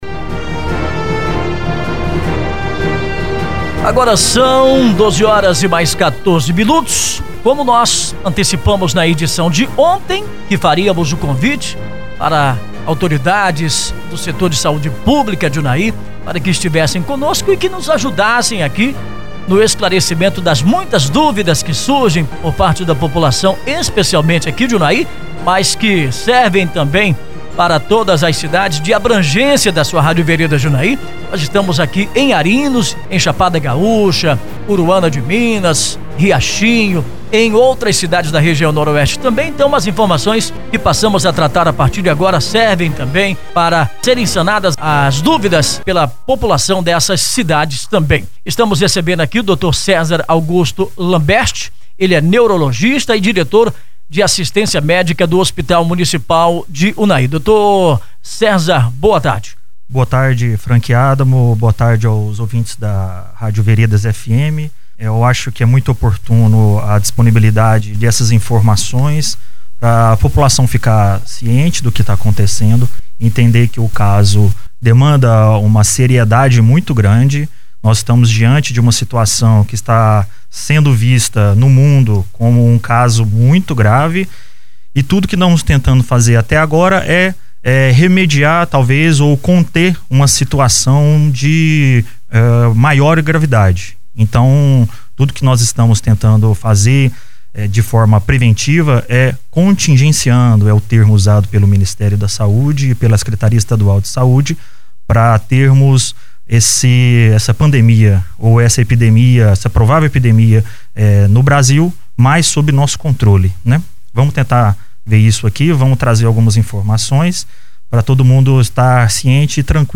O Médico foi entrevistado